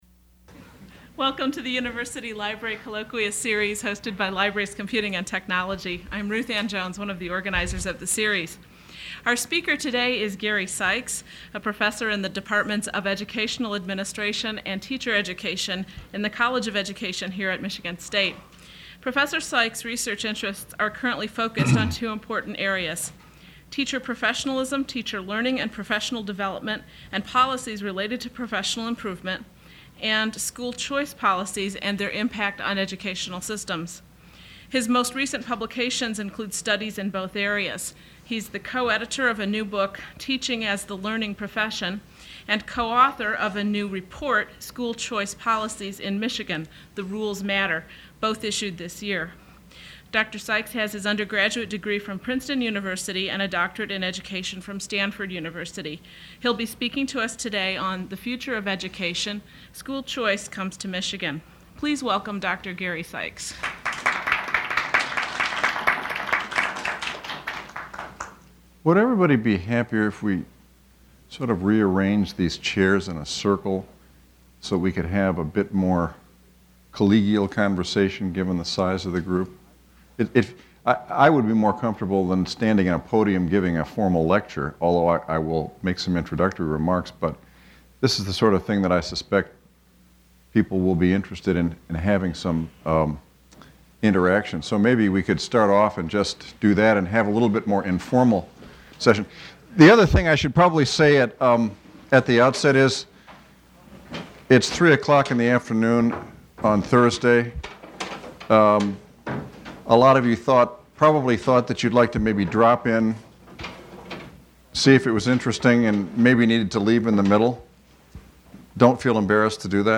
Questions from the audience follows.